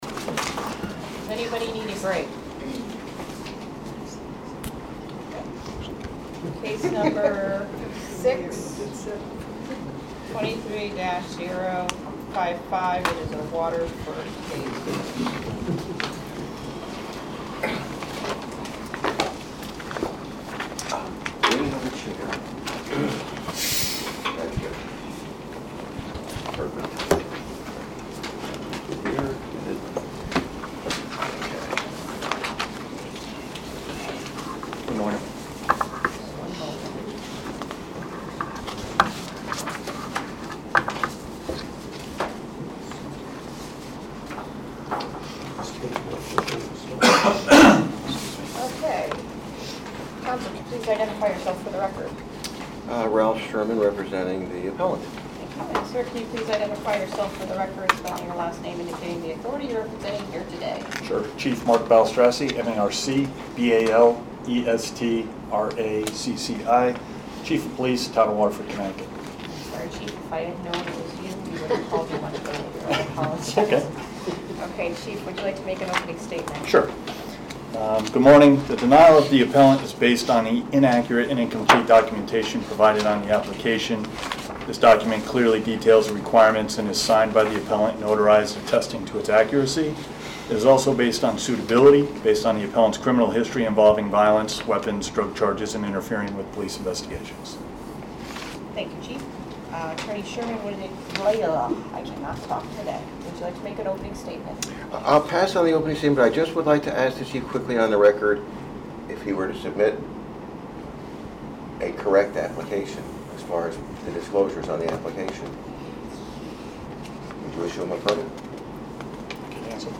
Meeting of the Board of Firearms Permit Examiners